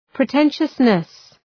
Προφορά
{prı’tenʃəsnıs}